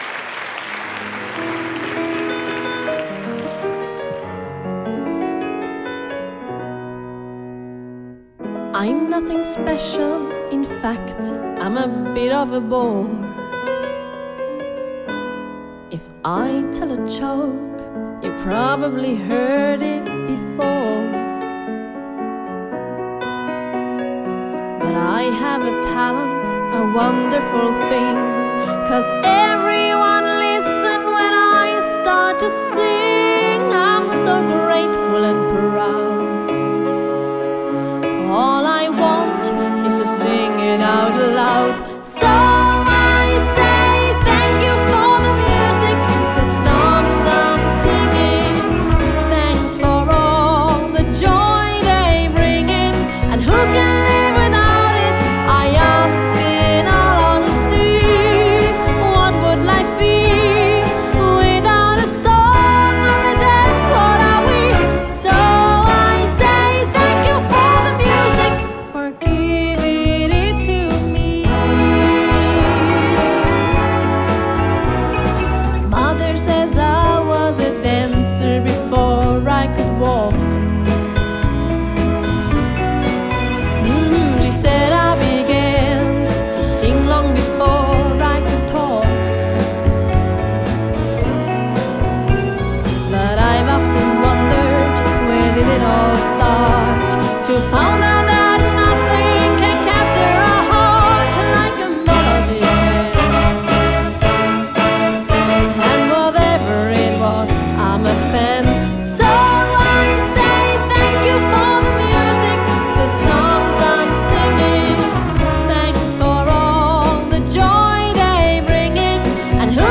Vocals
Trumpet
Trombone
Saxophone
Drums, Percussion
Bassguitar
Guitar
Keyboard